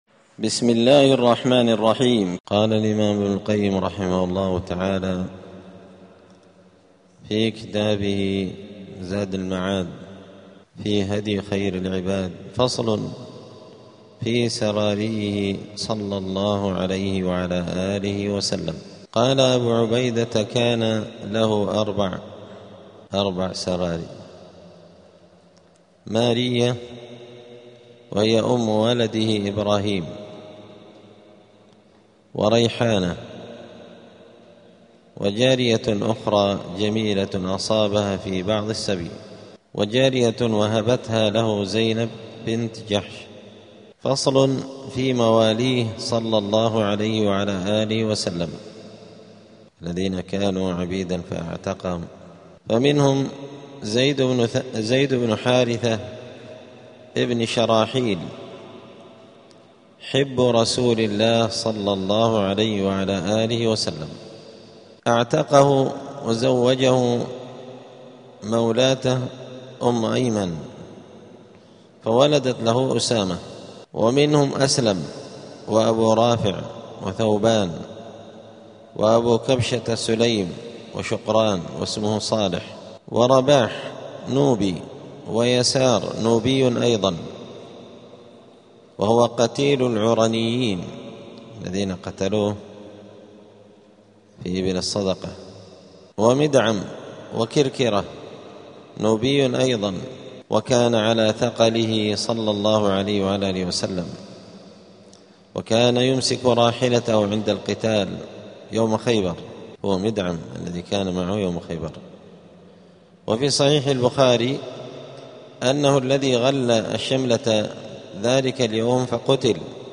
*الدرس العشرون (20) {ﻓﺼﻞ ﻓﻲ ﺳﺮاﺭﻳﻪ ومواليه ﺻﻠﻰ اﻟﻠﻪ ﻋﻠﻴﻪ ﻭﺳﻠﻢ}.*
دار الحديث السلفية بمسجد الفرقان قشن المهرة اليمن